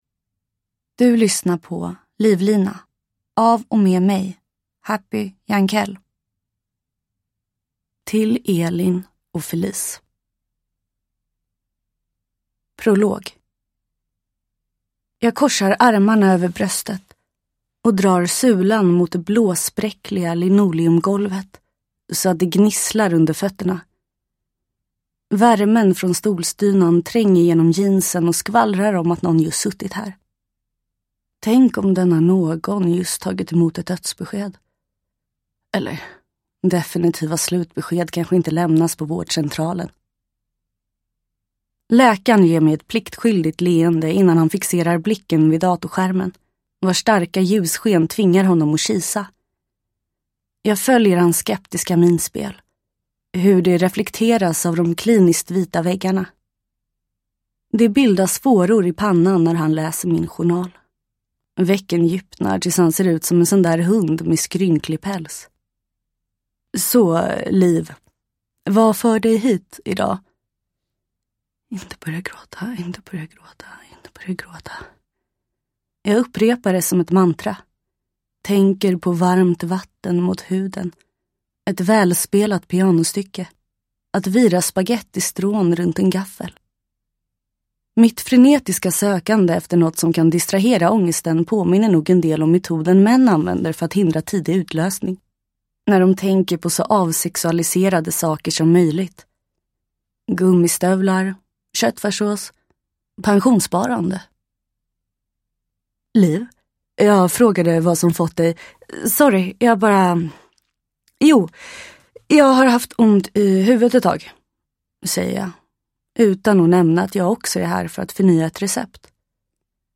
Livlina – Ljudbok – Laddas ner
Uppläsare: Happy Jankell